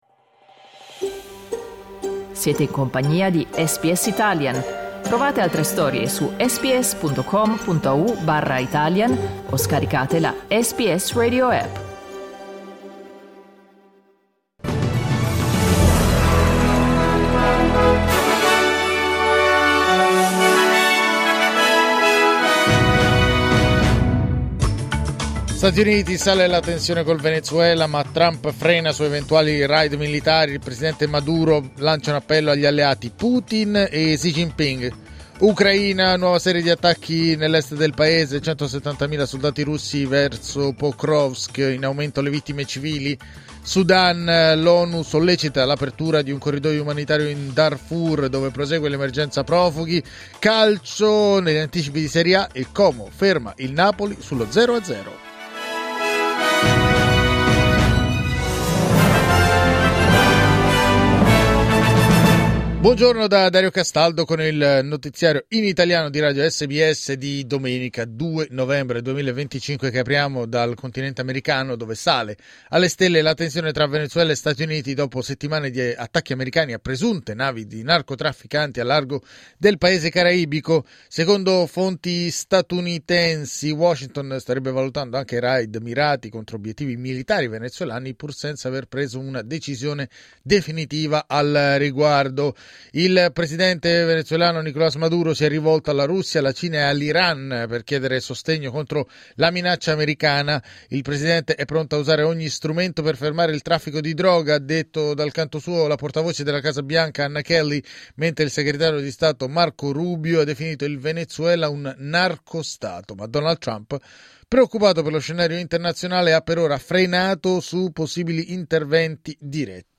Giornale radio domenica 2 novembre 2025
Il notiziario di SBS in italiano.